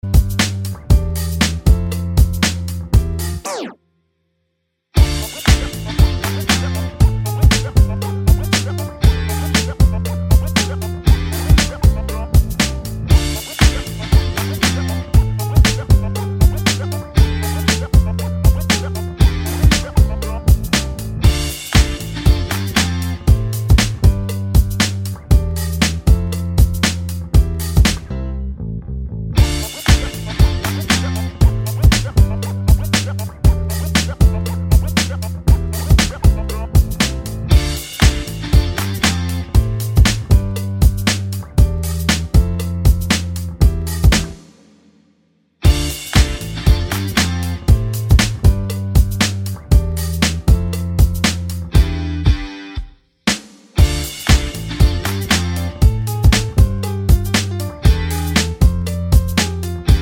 no Backing Vocals R'n'B / Hip Hop 3:58 Buy £1.50